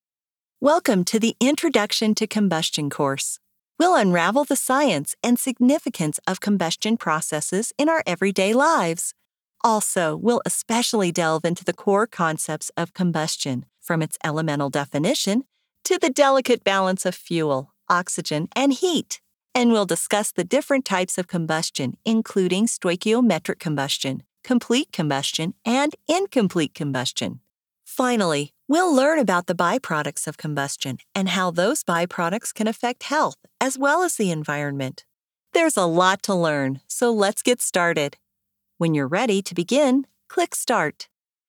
E-Learning Demo
E-Learning.mp3